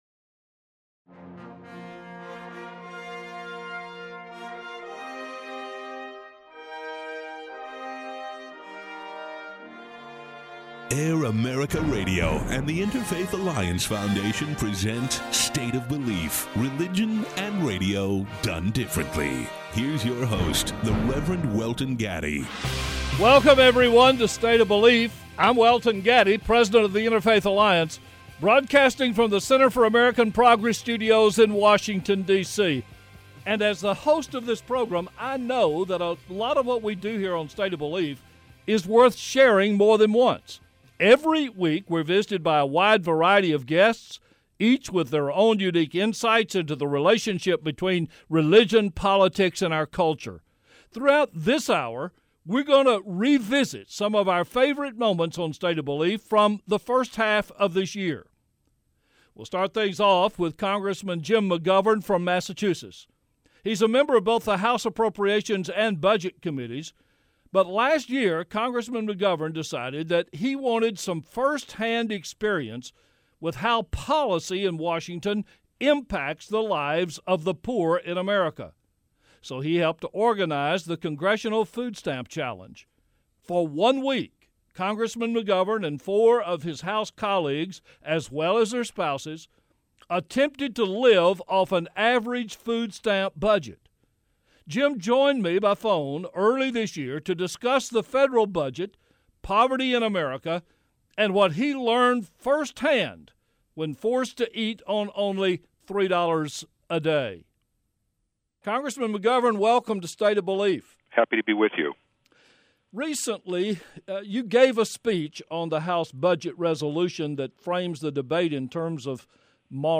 This week on State of Belief we revisit some of our favorite interviews from the first half of 2008.